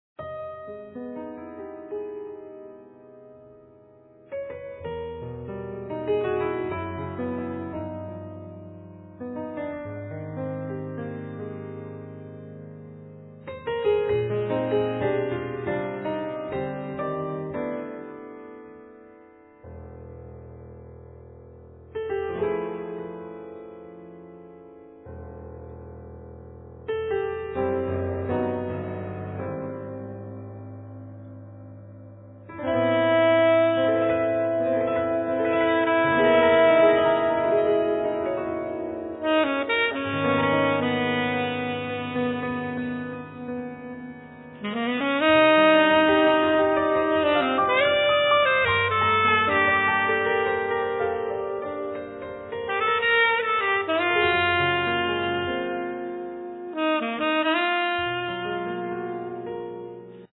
Piano
Drums
Double bass
Saxophone